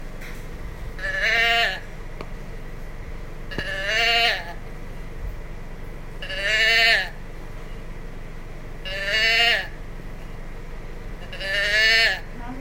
59mm Manual Teddy Bear Growler 1
This is Teddy Bear Growler 1
59mm Teddy Bear Manual Growlers – Sound 1 Growler Box 1 is slightly higher in pitch Sew these into your bear or soft toys to give your teddy bear a voice Tip the animal over to hear the growler.
GrowlerBox1.m4a